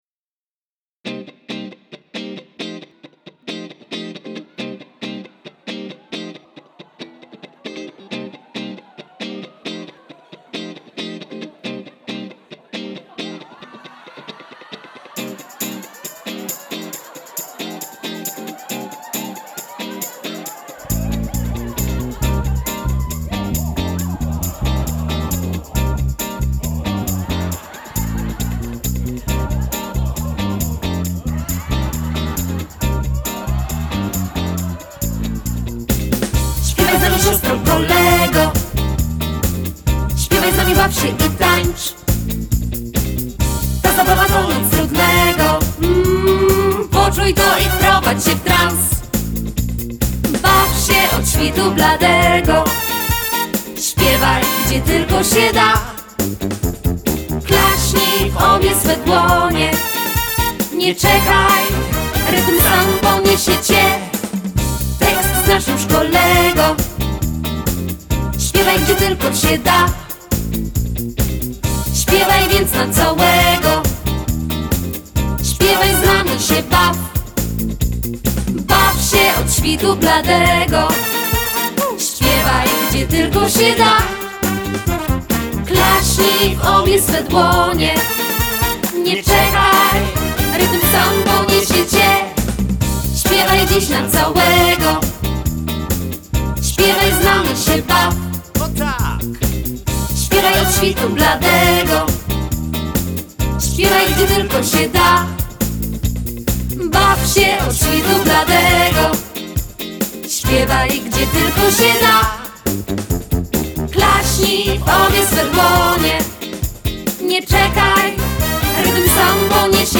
Nagrania, fotogalerie z Koncertów Konkursowych Ogólnopolskiego Festiwalu Piosenki im. Henryka Hampla oraz pliki do pobrania.